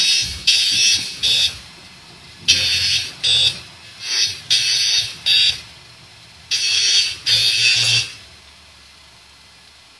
Звук пожарной тревоги
firealarm1.wav